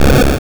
shooter.wav